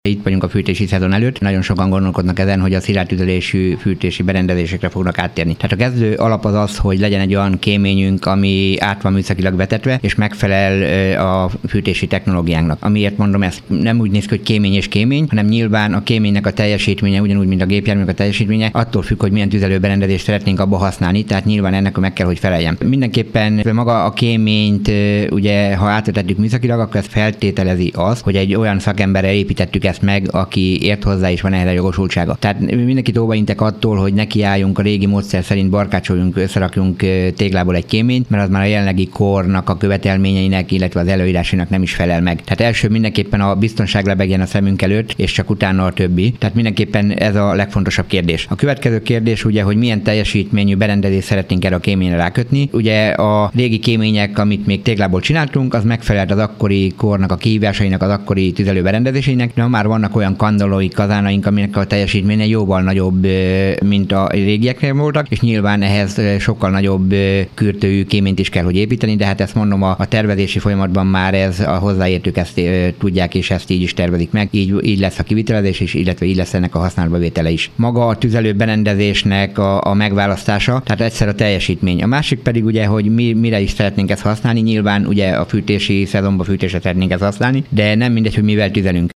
Marton Zsolt, a váci egyházmegye megyéspüspöke arról beszélt, a rendszerváltozás utáni évek új valóságot teremtettek az emberek életében.